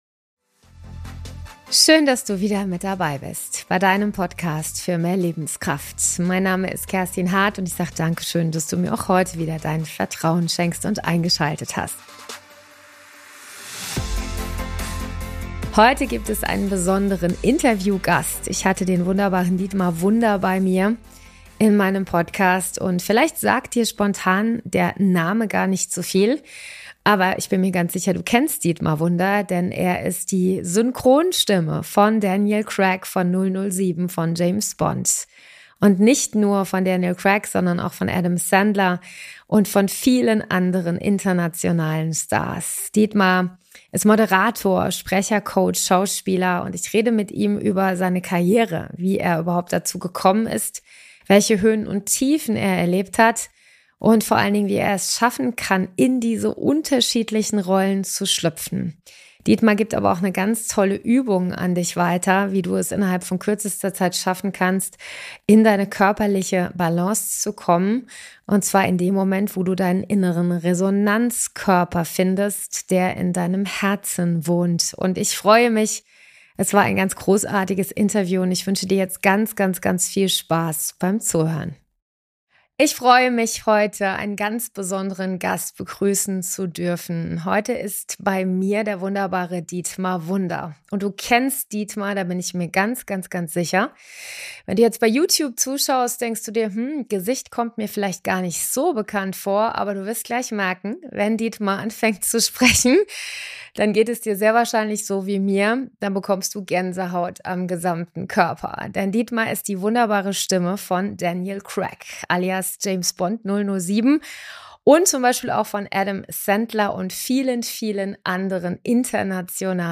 #680 - Dietmar Wunder öffnet die Tür zu seiner faszinierenden Welt - Interview mit der deutschen Stimme von "007" ~ Mehr Lebenskraft
Ein sehr interessantes, offenes und witziges Gespräch voller Charme, in dem Dietmar uns mitnimmt in seine Welt der Prominenten, aber auch hinter die Kulissen blicken lässt.